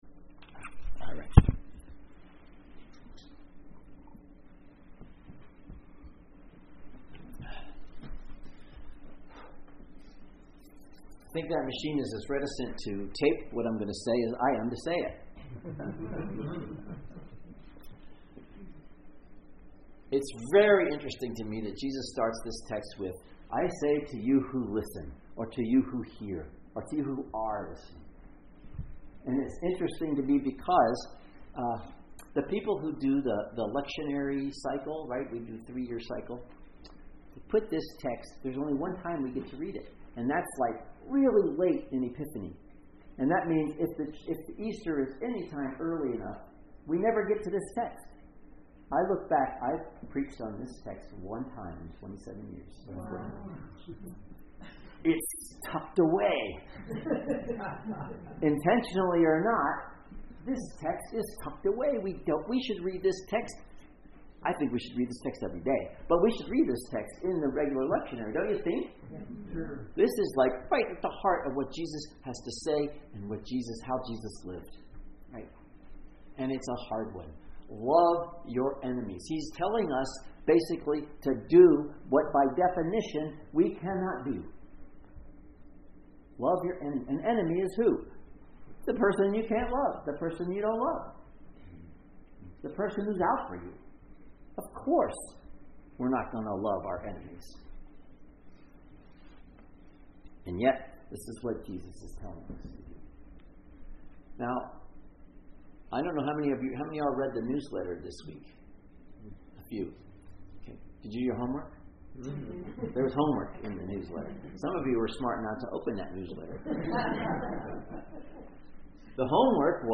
Sermons | Lake Chelan Lutheran Church